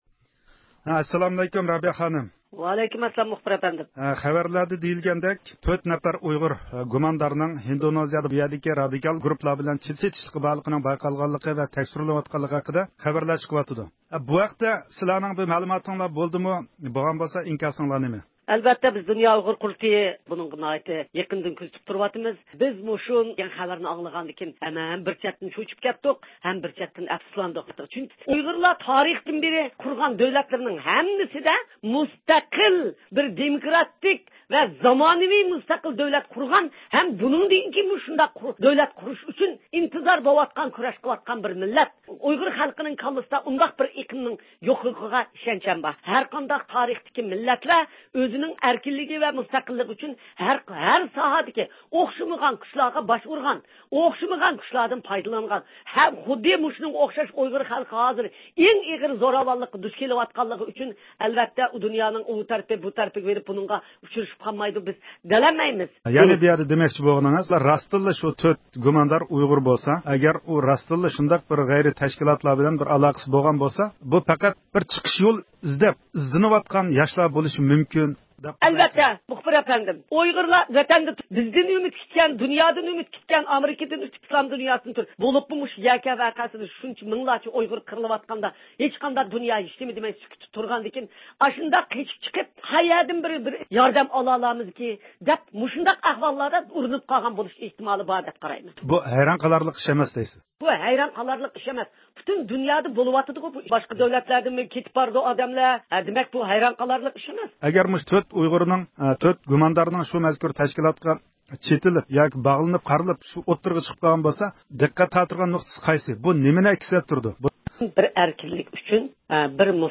دۇنيا ئۇيغۇر قۇرۇلتىيى رەئىسى رابىيە قادىر خانىم تۈنۈگۈن رادىئومىز زىيارىتىنى قوبۇل قىلىپ 4 نەپەر ئۇيغۇرنىڭ ھىندونېزىيەدىكى ئىراق-شام ئىسلام دۆلىتى تەرەپدارلىرى بىلەن چېتىشلىق دەپ قارىلىپ تېررورلۇق گۇمانى بىلەن تۇتقۇن قىلىنىشى ھەققىدە پوزىتسىيە بىلدۈردى.
يۇقىرىدىكى ئاۋاز ئۇلىنىشىدىن، ئۇيغۇر مىللىي ھەرىكىتى رەھبىرى رابىيە قادىر خانىم مۇخبىرىمىزنىڭ سۆھبىتىنى ئاڭلىغايسىلەر.